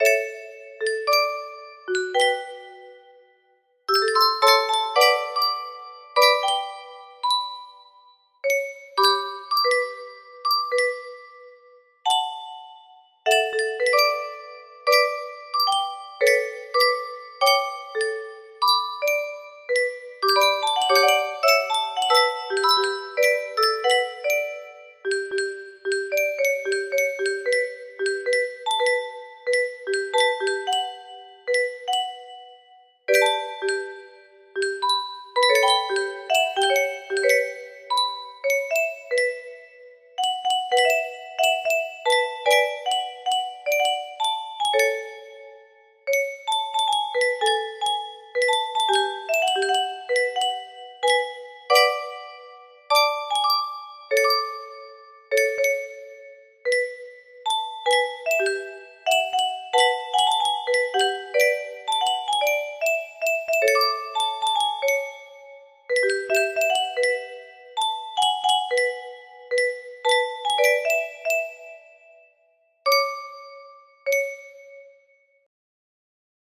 Imported from MIDI MIDI UNCON.mid